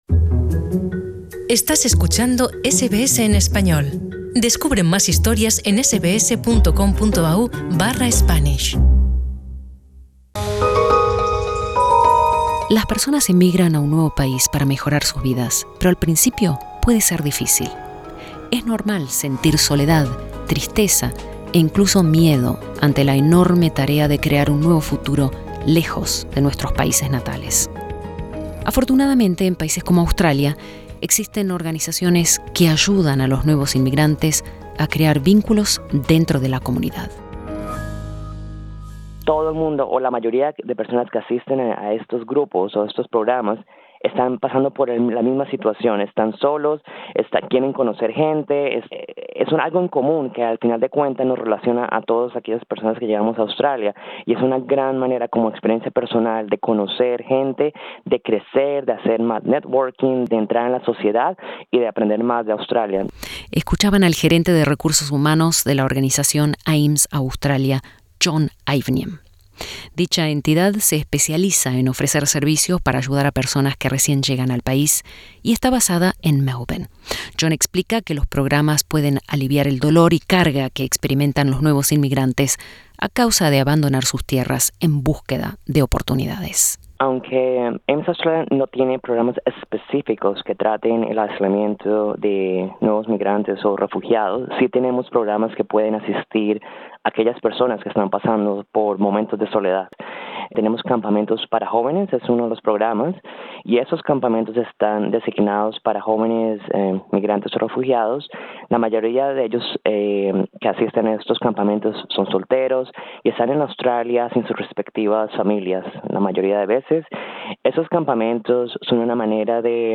Existen organismos en algunas ciudades australianas que ofrecen recursos para lidiar con la soledad que muchos inmigrantes experimentan cuando llegan Australia, debido al desarraigo, la barrera idiomática y las dificultades de integración cultural. Escucha nuestro informe.